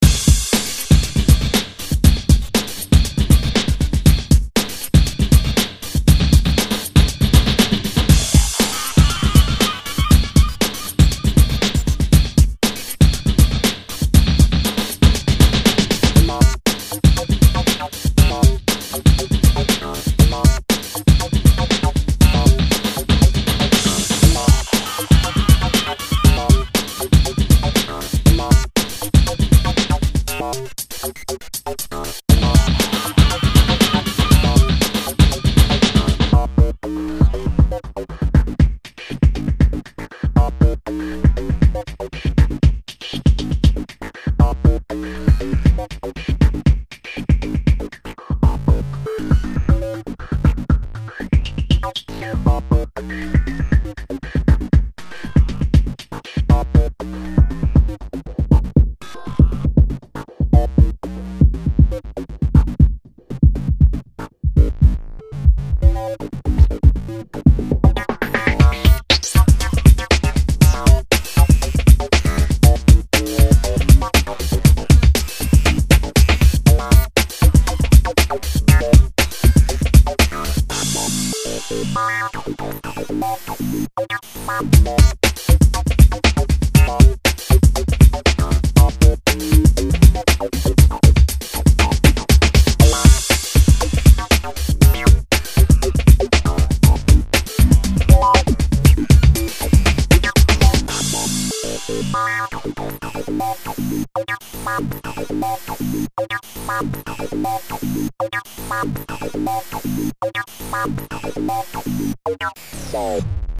A hard-hitting techno track